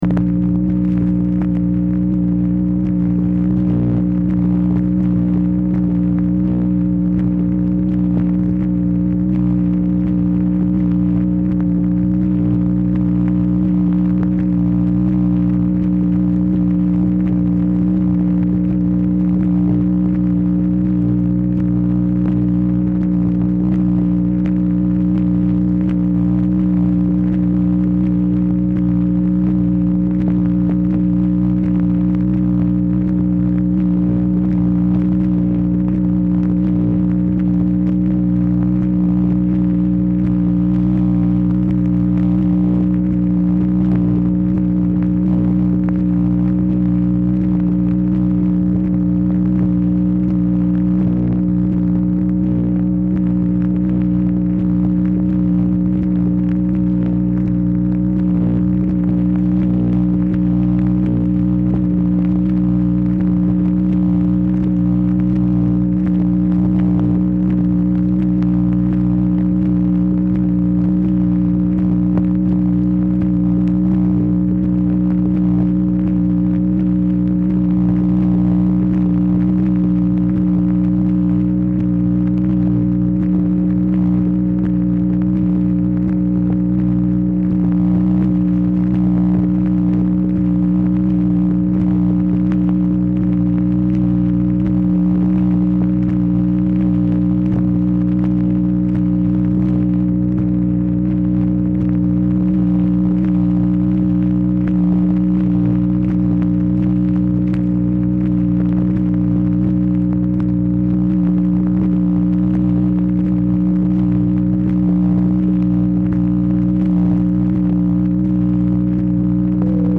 Telephone conversation # 13562, sound recording, MACHINE NOISE, 10/18/1968, time unknown · Discover Production
Format: Dictation belt